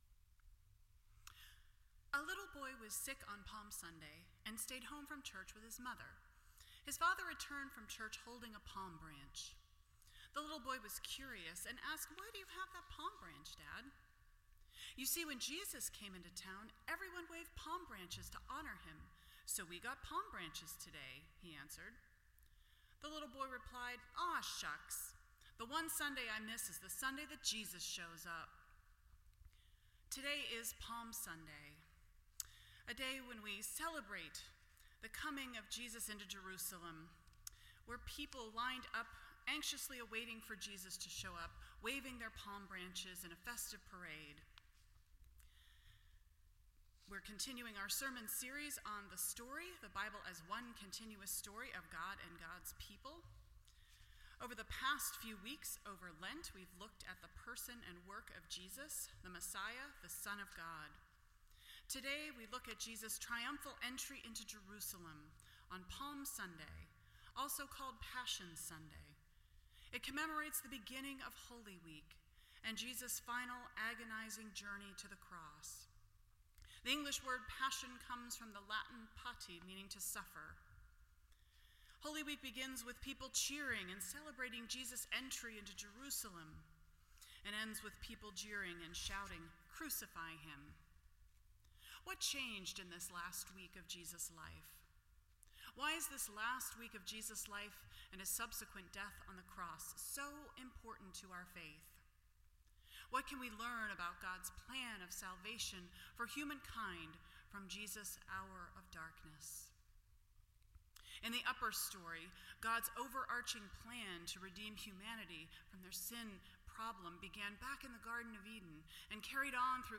The Story Service Type: Palm Sunday %todo_render% Share This Story